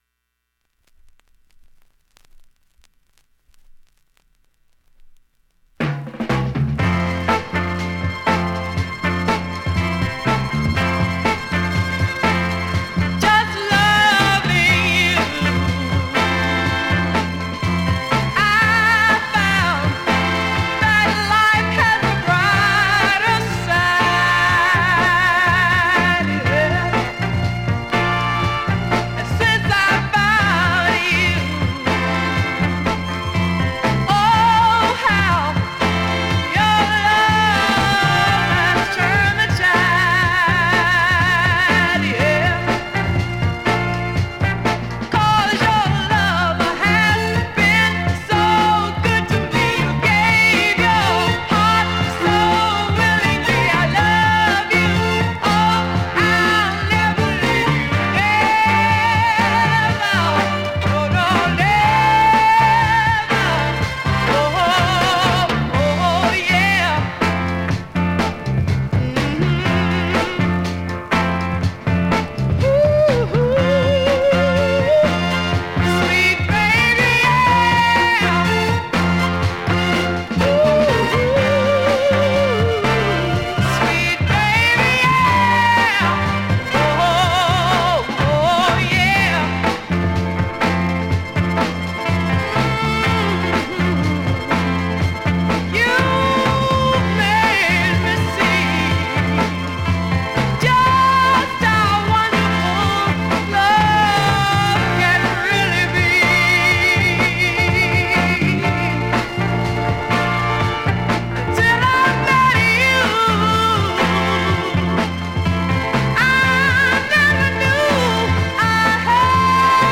現物の試聴（両面すべて録音時間6分9秒）できます。